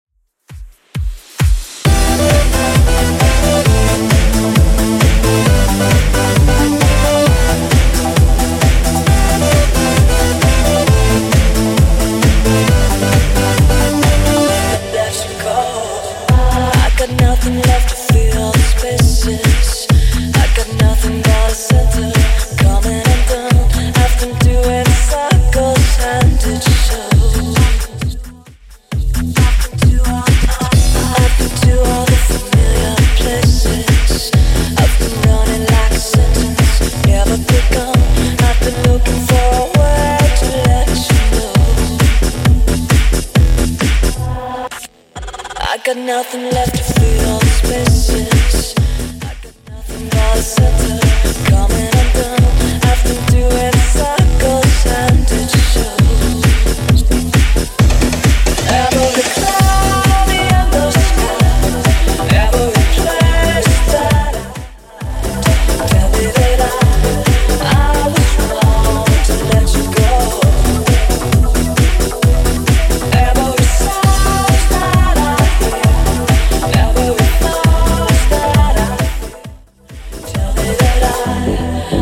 Genre: 90's
BPM: 142